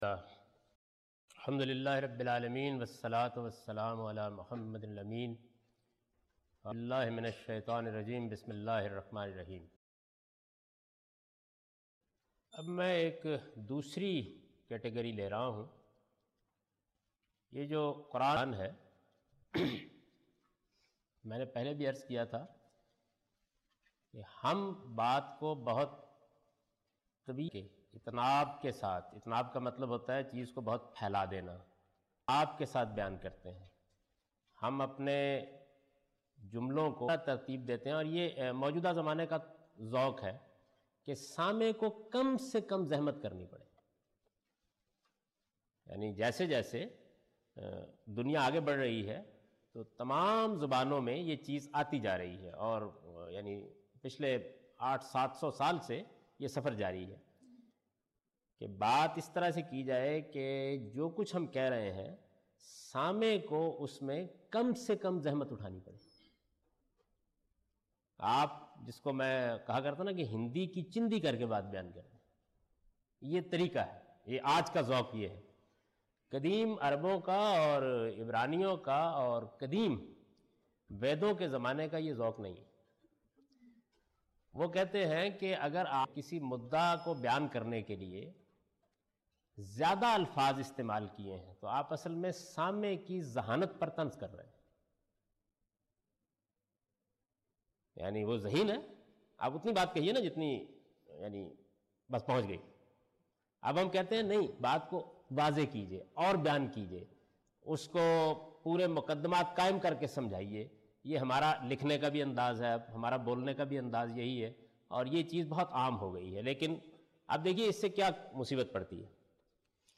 A comprehensive course on Islam, wherein Javed Ahmad Ghamidi teaches his book ‘Meezan’.
In this lecture he teaches the importance of appreciation of classical Arabic in order to truly understand Quran. (Lecture no.13 – Recorded on 28th February 2002)